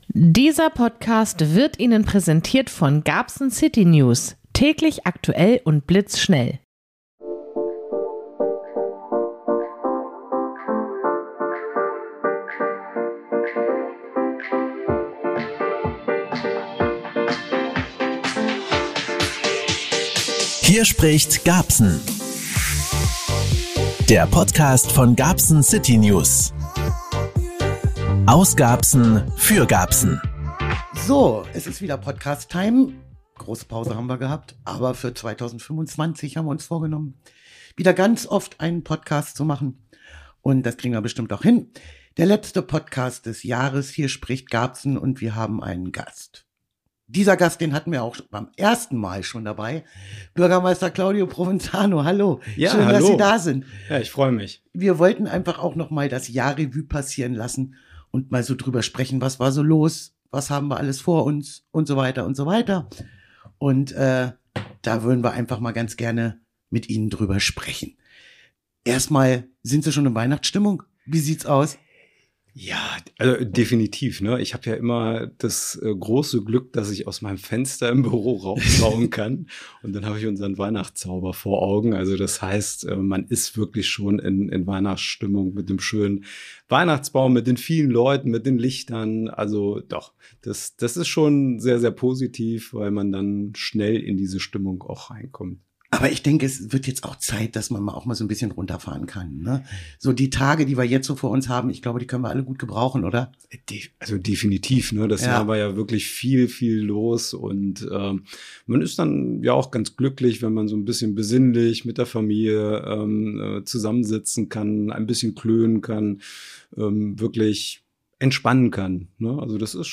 Tschüss 2024 Hallo 2025! Gast: Bürgermeister Provenzano ~ Hier spricht GARBSEN!